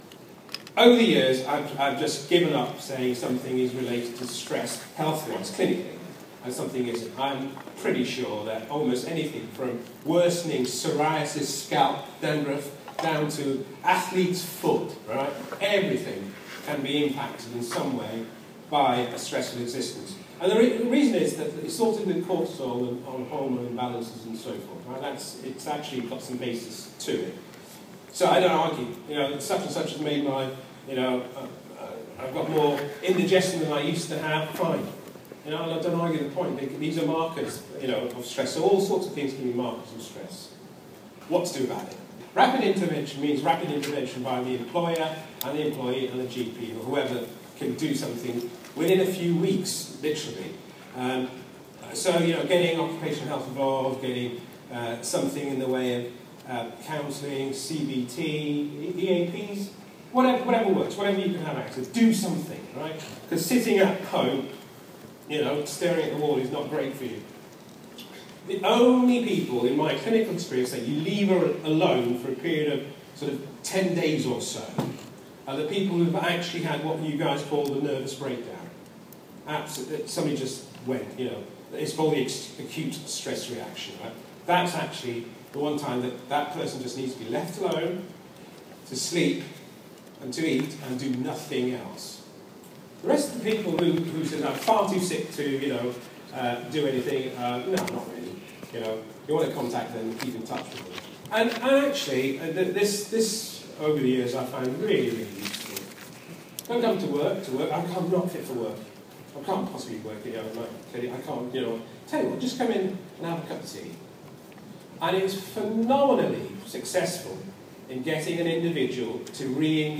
IOSH 2015, held at ExCeL London on 16-17 June 2015, featured dedicated sessions on the topic of occupational health.